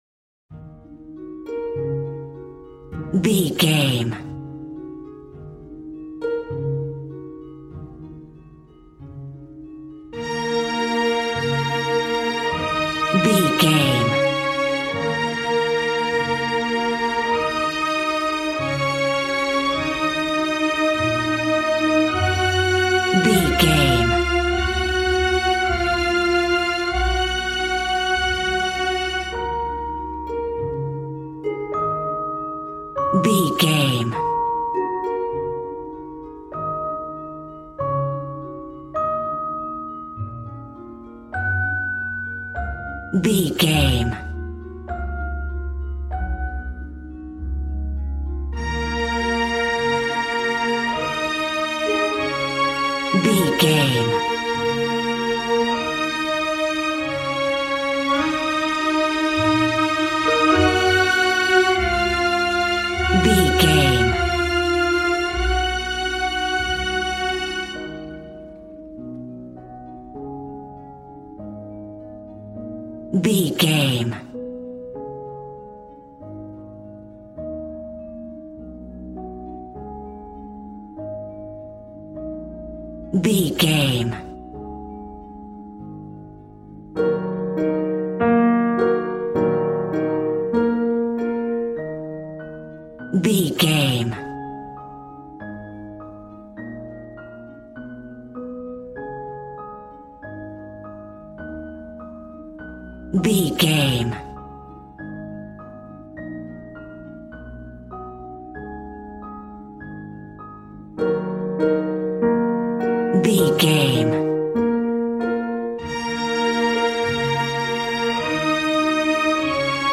Regal and romantic, a classy piece of classical music.
Aeolian/Minor
E♭
Fast
regal
strings
violin